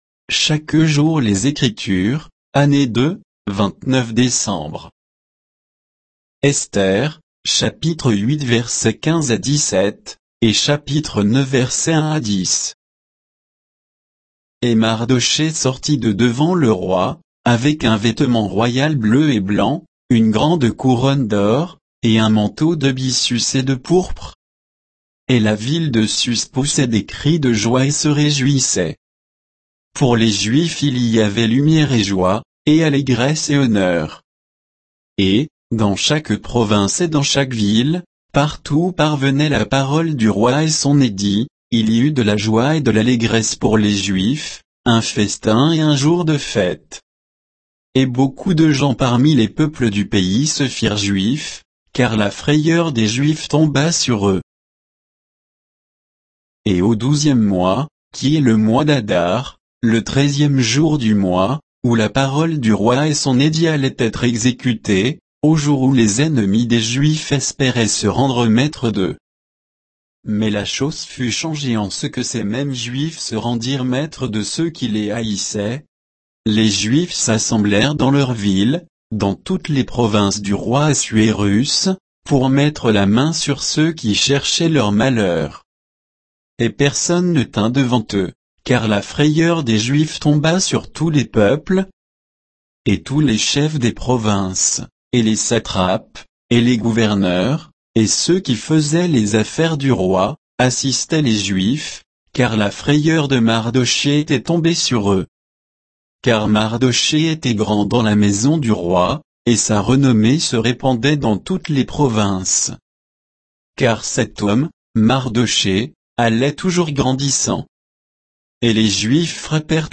Méditation quoditienne de Chaque jour les Écritures sur Esther 8